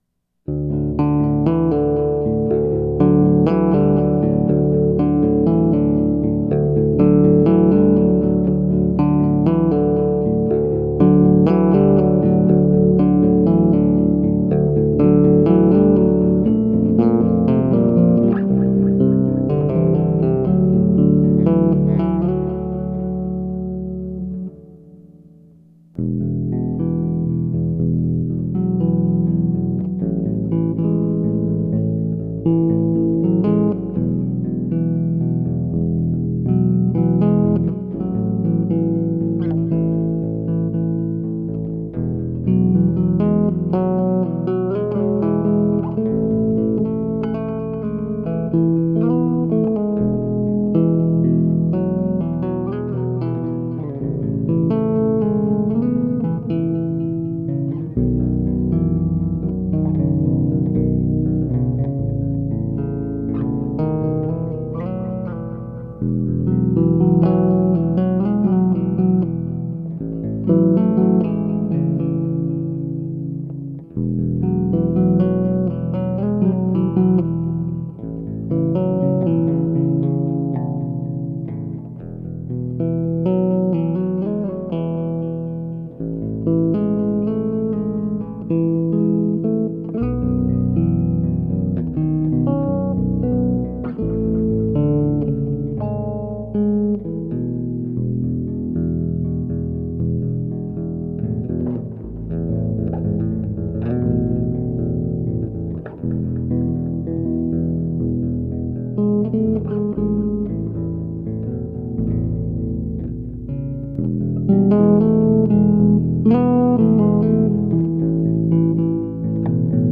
Climbing Snail (Bass duet improvisation)
electric bass
bass duet
improvisation
two-handed
tapping